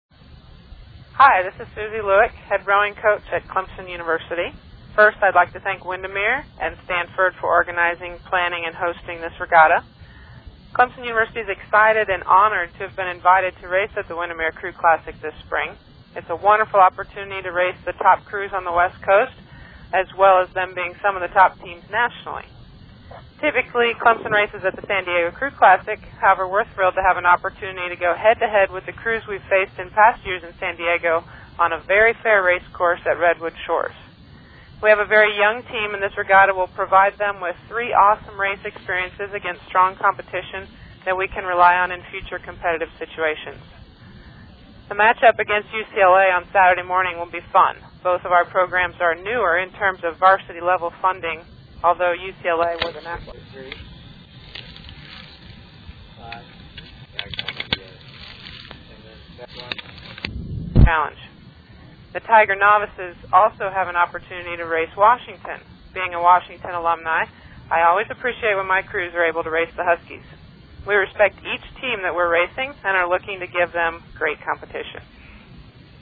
Team Head Coach Pre-race Comments